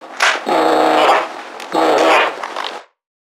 NPC_Creatures_Vocalisations_Infected [93].wav